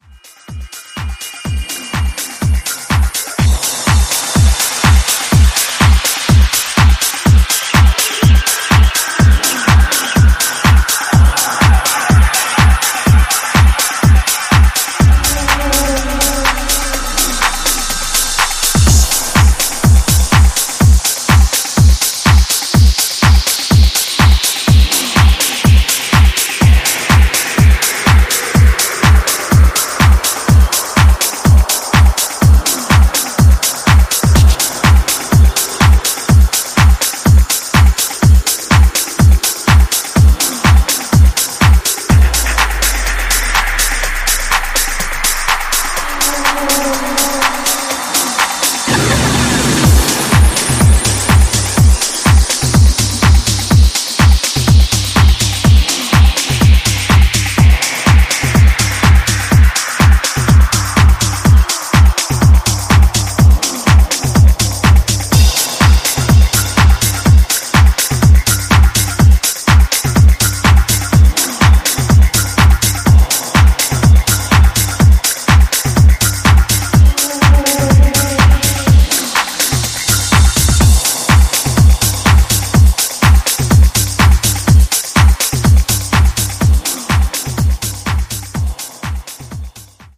また、B面にはビートレスのバージョンとビート主体の別バージョンも収録。'